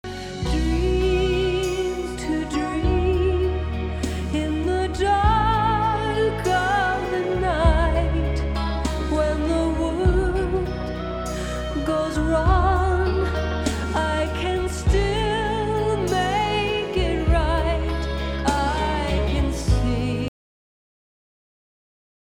Tukaj sem naredil par fileov za testiranje kvalitete mojega kodeka, mp3-ja in original wave-a (CDja).